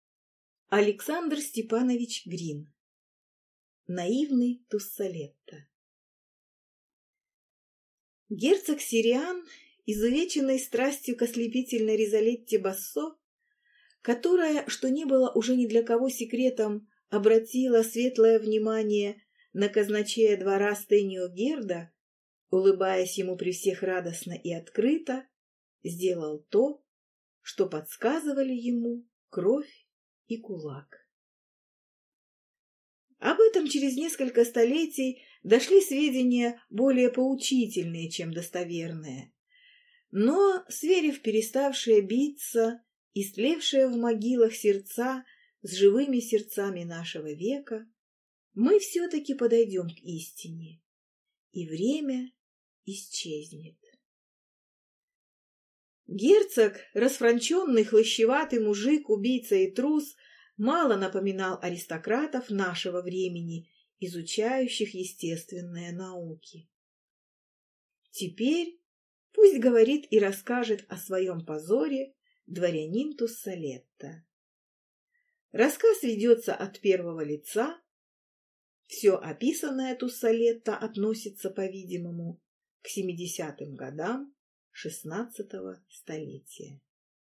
Аудиокнига Наивный Туссалетто | Библиотека аудиокниг